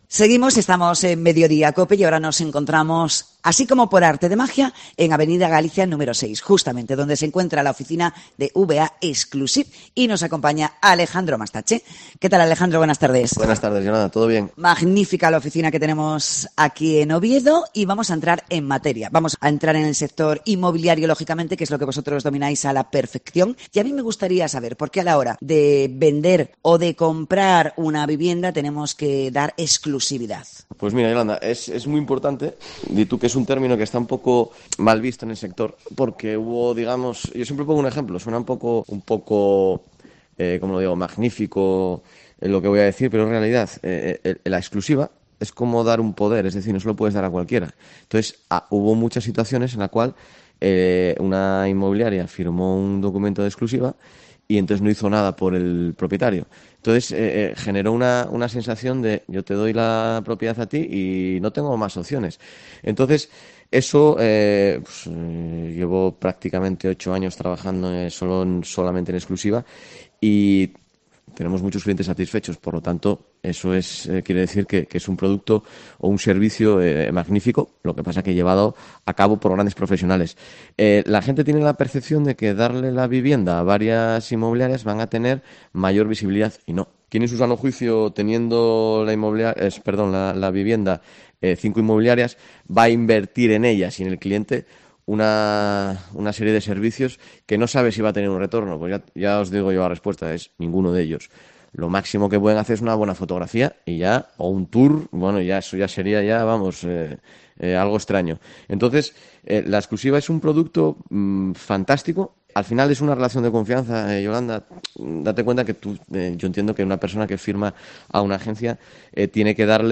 Entrevista desde la oficina ovetense de VA Exclusive